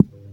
beeb kick 25 (feedback)
Tags: 808 drum cat kick kicks hip-hop